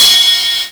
RIDE4     -L.wav